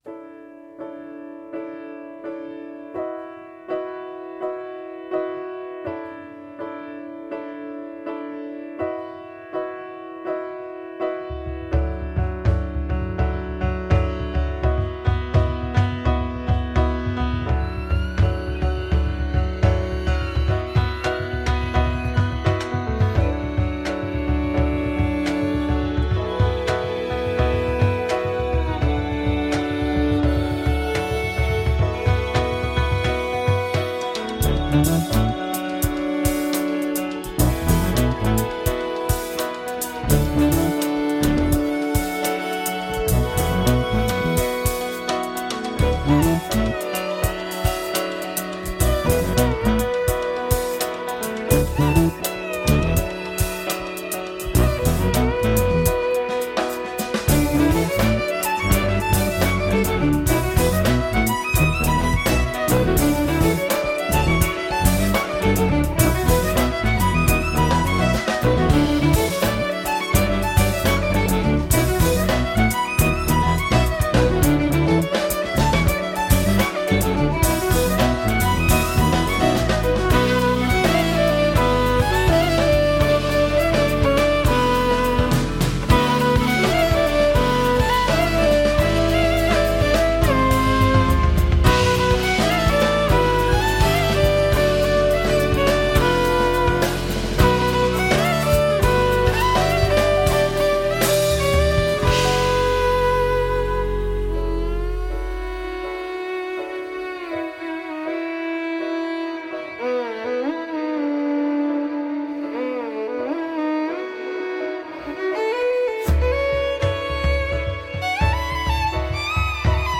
jazz
le violoniste